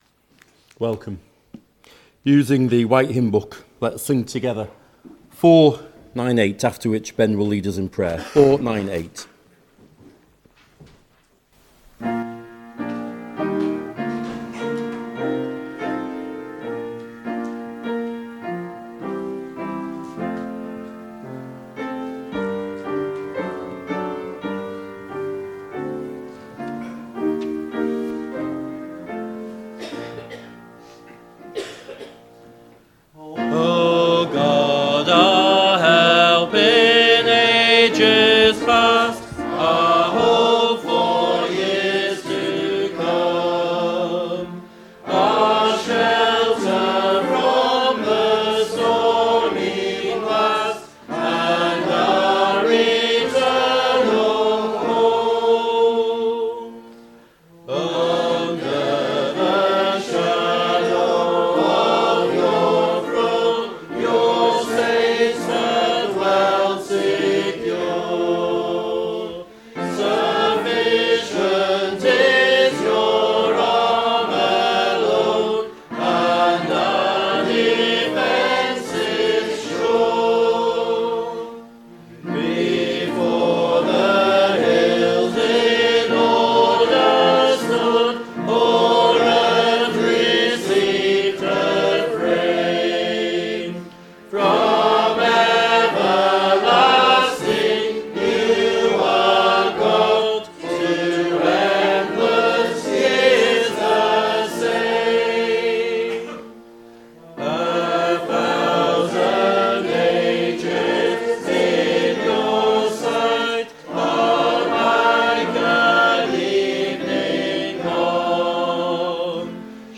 Below is audio of the full service.
2026-01-04 Evening Worship If you listen to the whole service on here (as opposed to just the sermon), would you let us know?